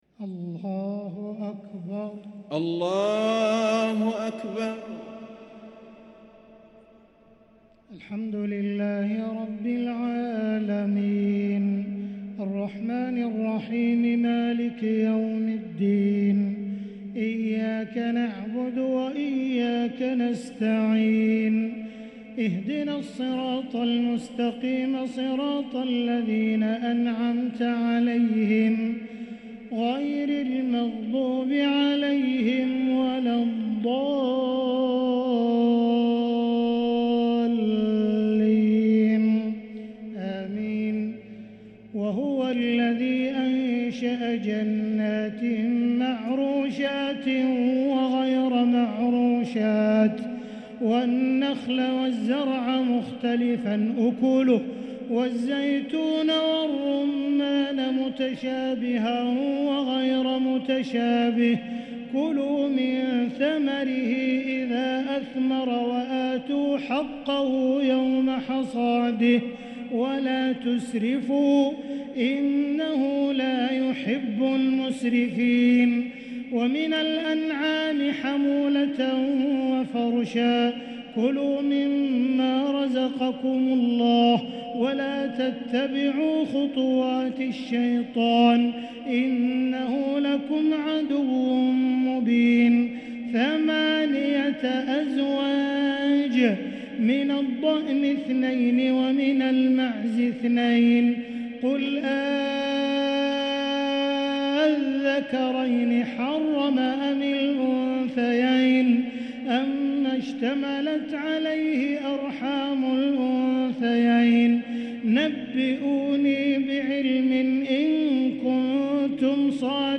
تراويح ليلة 10 رمضان 1444هـ من سورة الأنعام (141-165) | taraweeh 10st niqht ramadan Surah Al-Anaam1444H > تراويح الحرم المكي عام 1444 🕋 > التراويح - تلاوات الحرمين